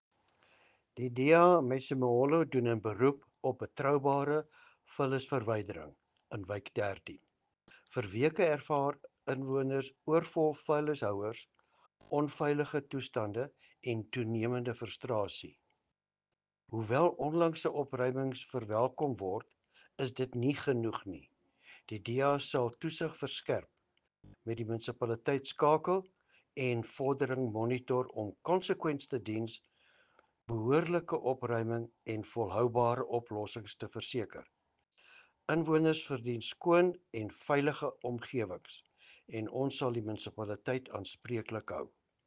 Afrikaans soundbite by Cllr Louis van Heerden and